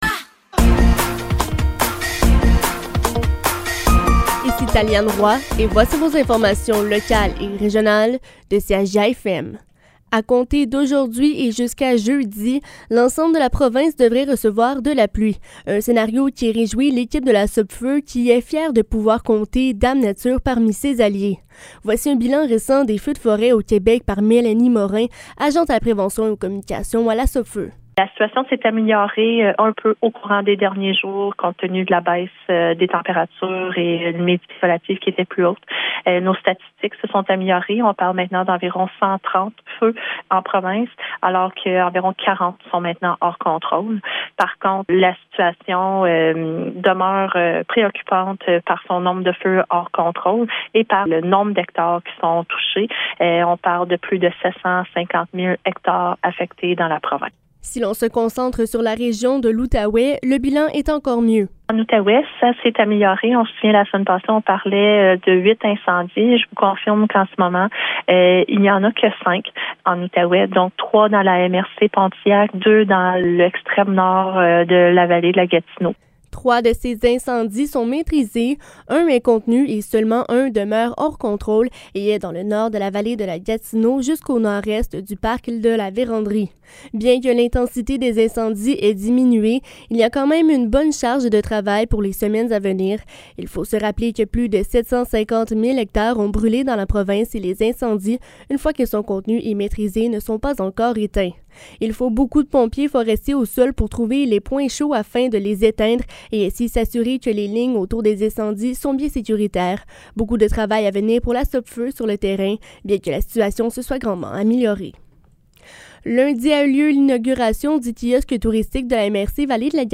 Nouvelles locales - 13 juin 2023 - 15 h